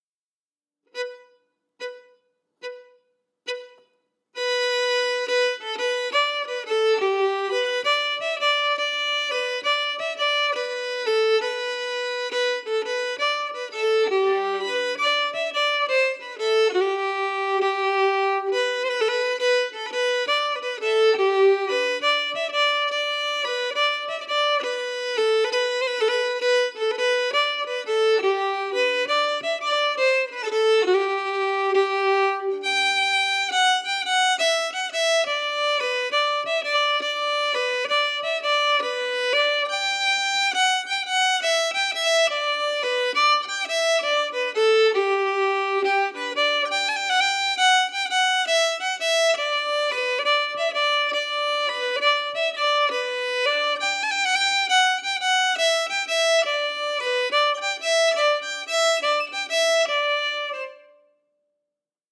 Key: G
Form: Reel
Harmony emphasis
Region: Ireland